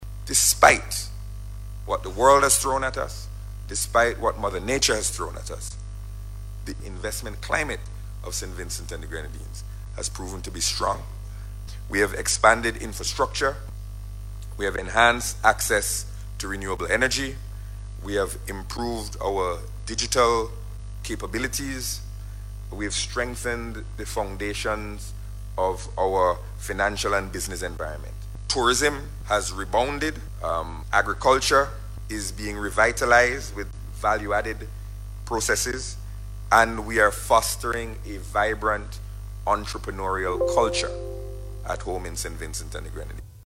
This, from Minister of Finance and Economic Planning, Camillo Gonsalves during his address at the Invest SVG Forum in the United Kingdom.